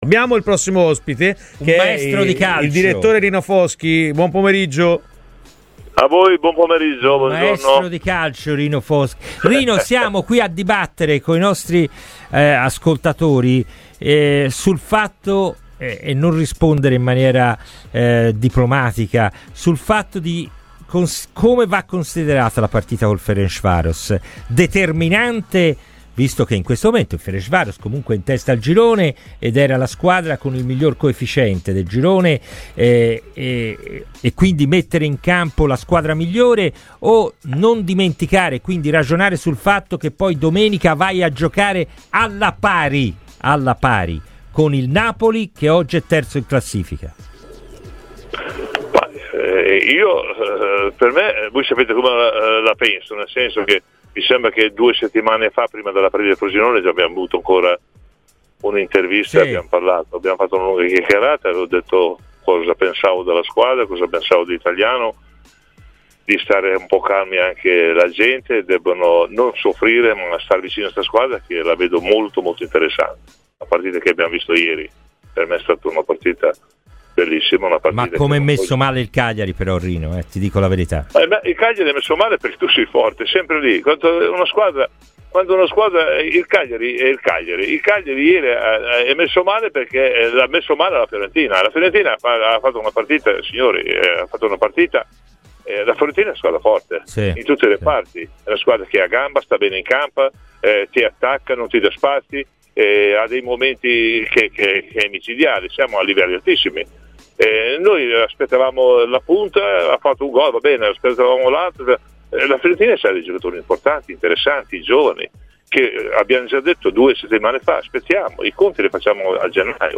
è intervenuto ai microfoni di Radio FirenzeViola.